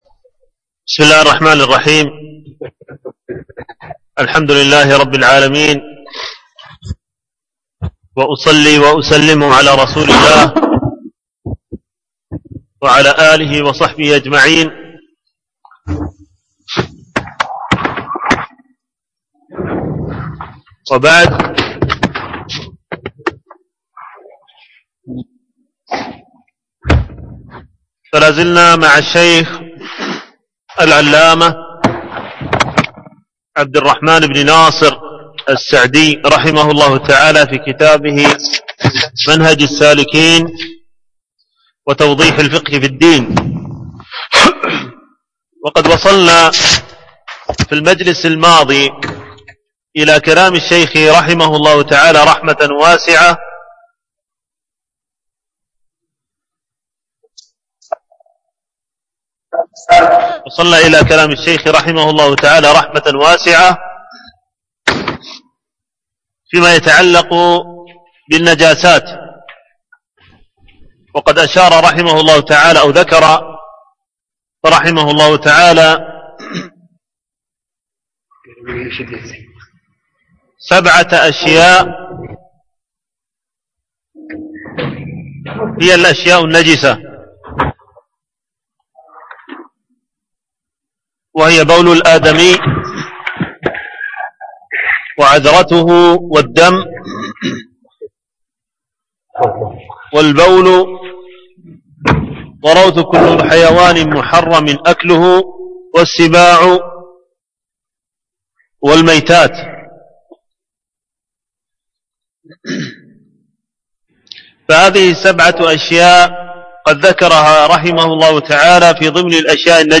العنوان: الدرس السابع